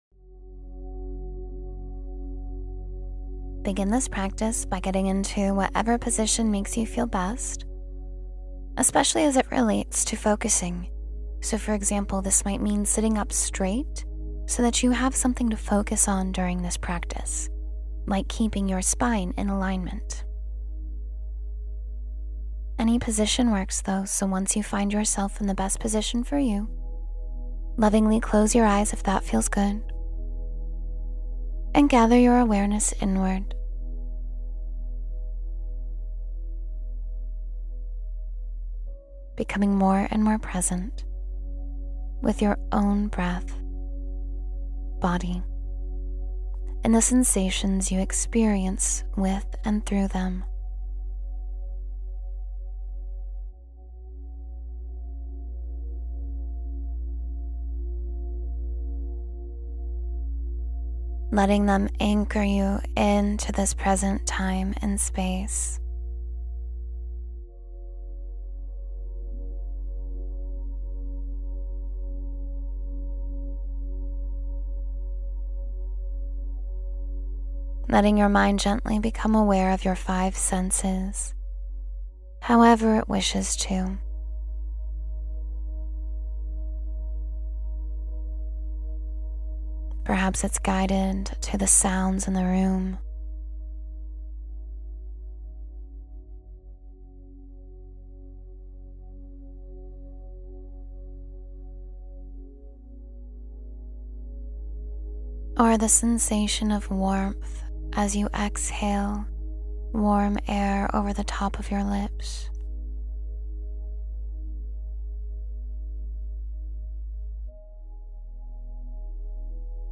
Focus and Clarity in Just 10 Minutes — Meditation for Mental Clarity